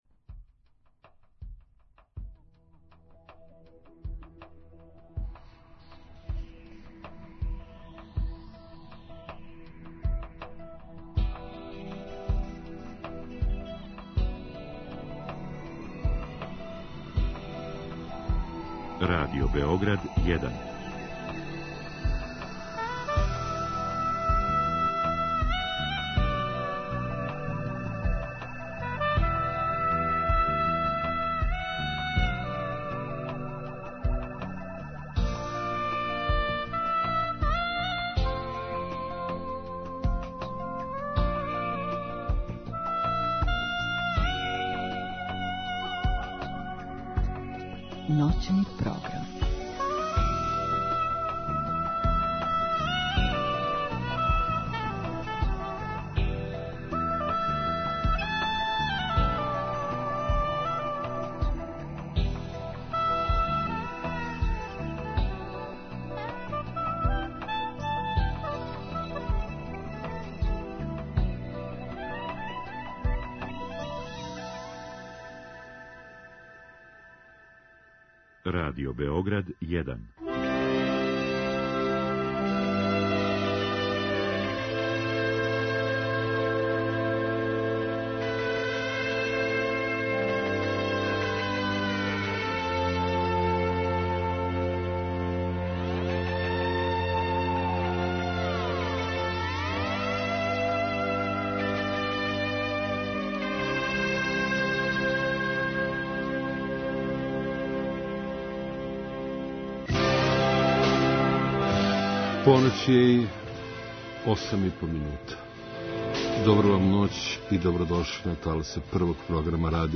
Уз незаборавне хитове тог времена поделите и ви са нама успомене на те две деценије.